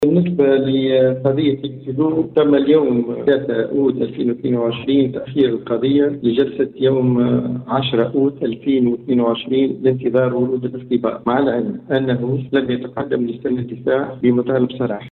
في تصريح ل “ام اف ام”